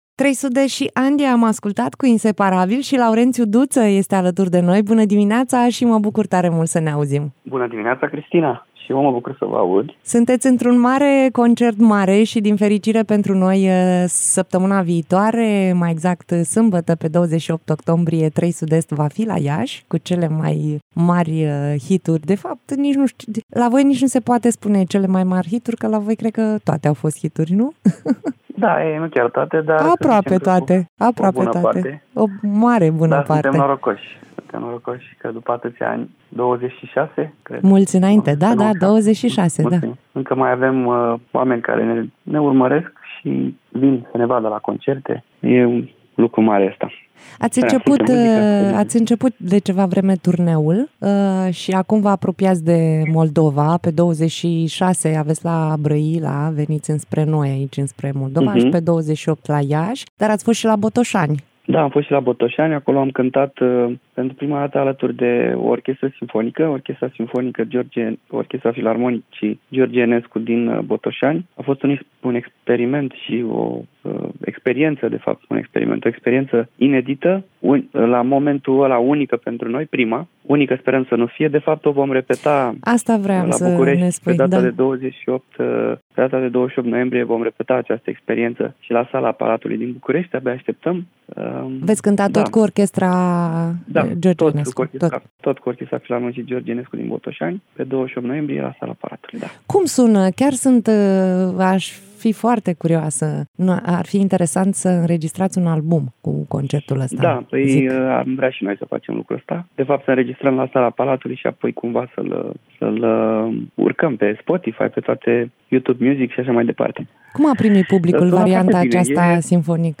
(INTERVIU) Laurențiu Duță